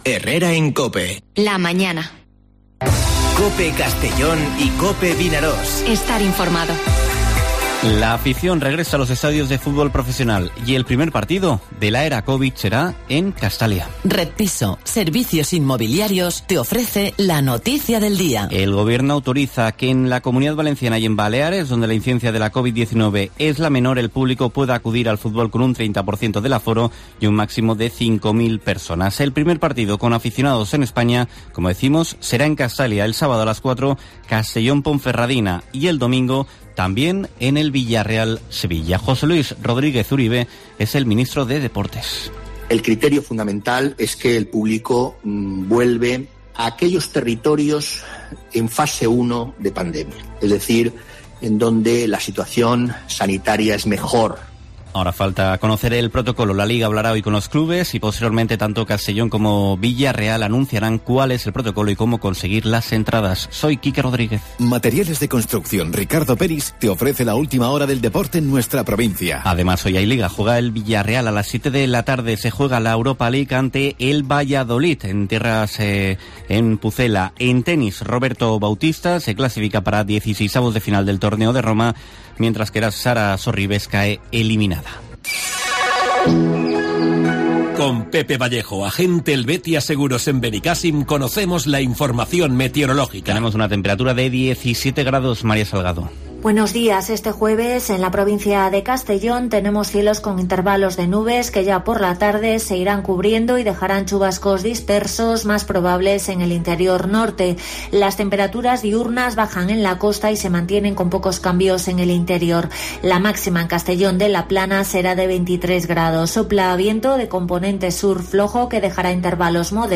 Informativo Herrera en COPE en la provincia de Castellón (13/05/2021)